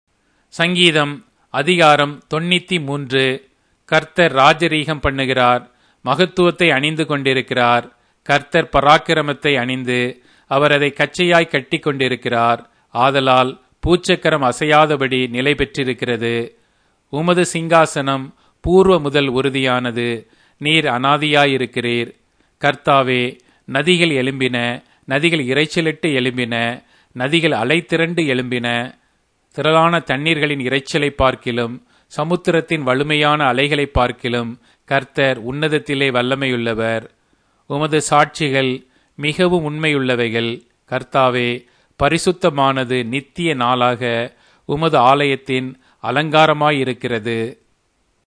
Tamil Audio Bible - Psalms 150 in Hov bible version